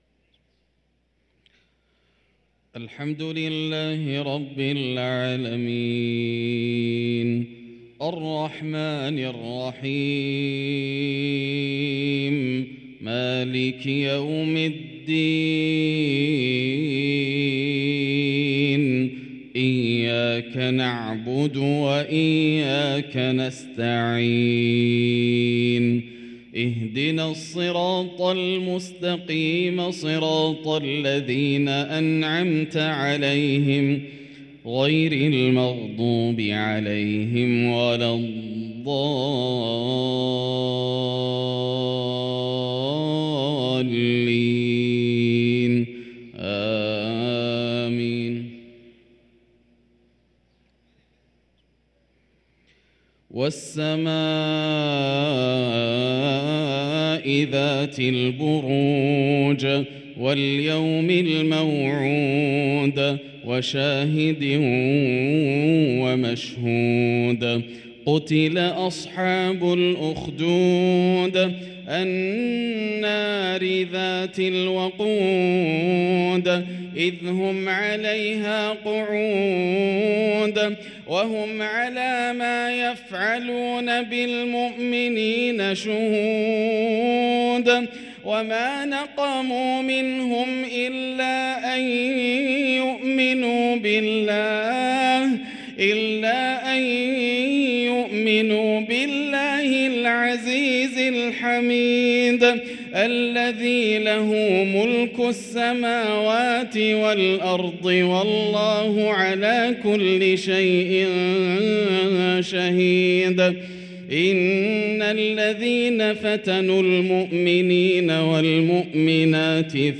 صلاة العشاء للقارئ ياسر الدوسري 10 شعبان 1444 هـ